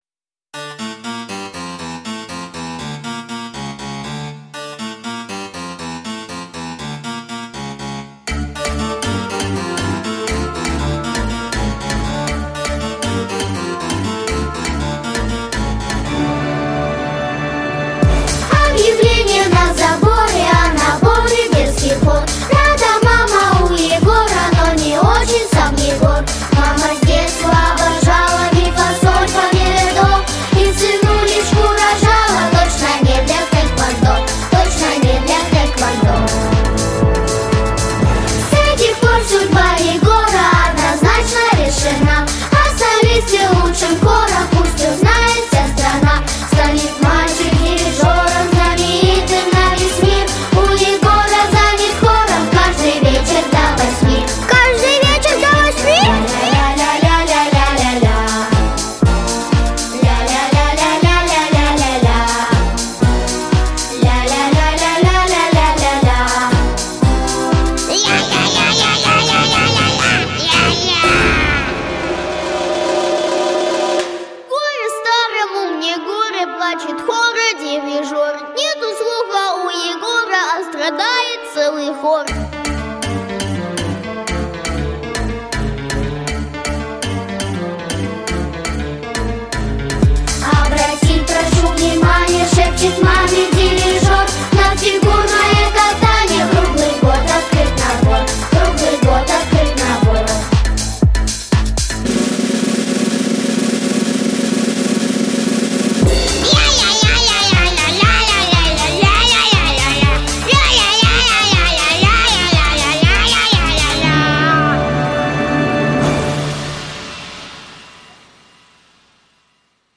• Жанр: Детские песни
Детская песня